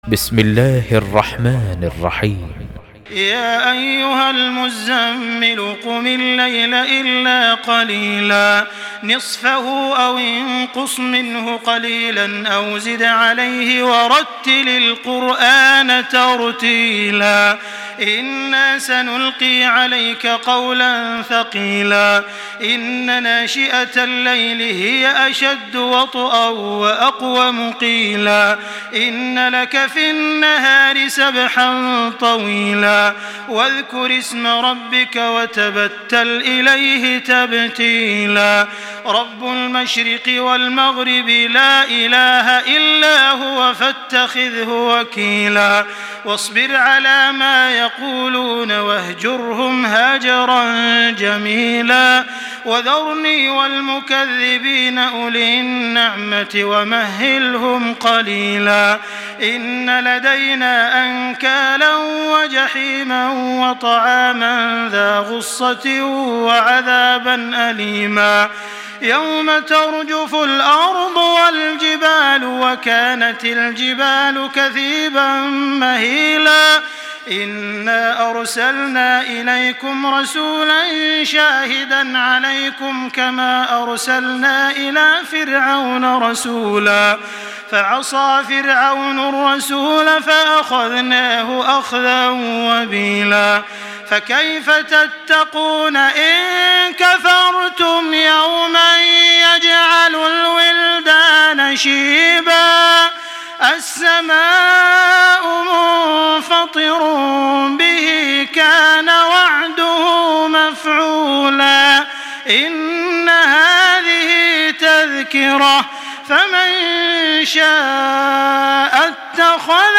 Surah আল-মুযযাম্মিল MP3 by Makkah Taraweeh 1425 in Hafs An Asim narration.
Murattal